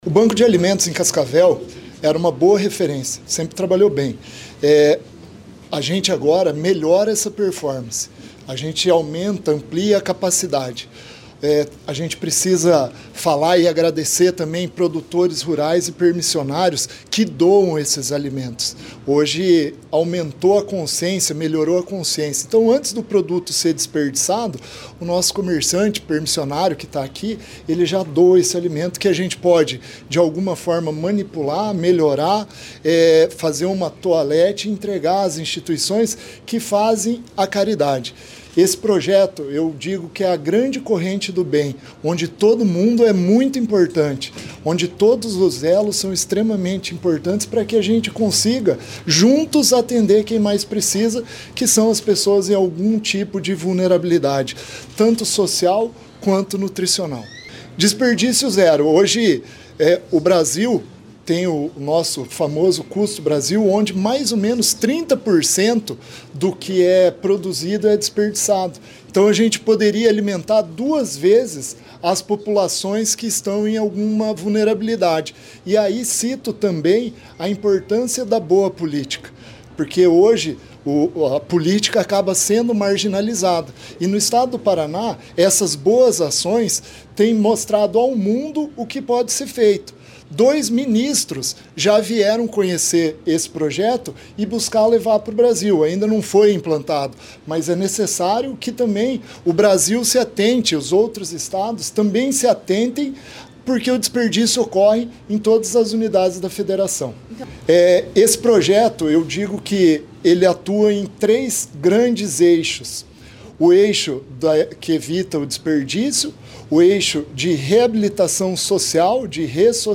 Sonora do diretor-presidente da Ceasa Paraná, Éder Bublitz, sobre a modernização do Banco de Alimentos da Ceasa Cascavel